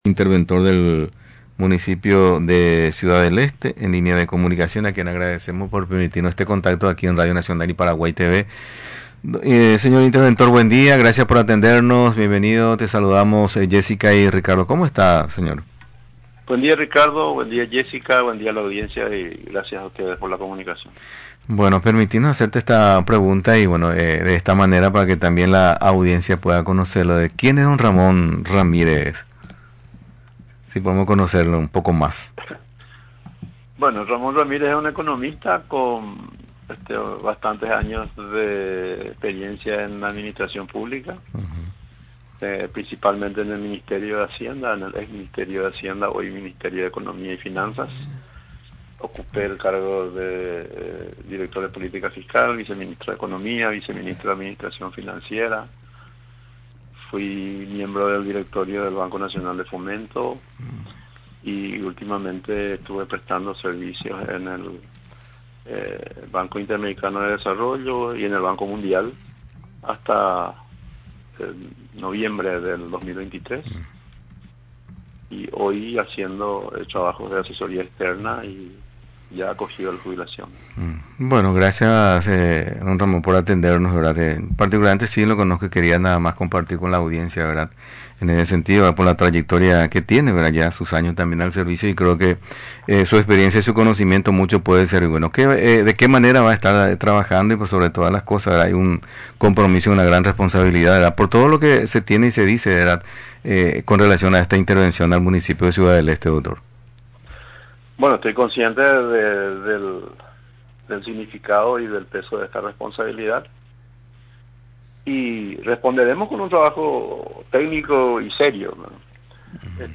Durante la entrevista en Radio Nacional del Paraguay, confirmó el comienzo de las tareas en la comuna esteña. Además refirió sobre los trabajos que desarrollarán en este tiempo a fin de observar y corroborar las denuncias que pesan sobre la administración actual.